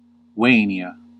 Ääntäminen
IPA : /ɪnˈdʌld͡ʒəns/